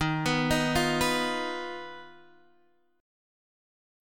D#sus2#5 chord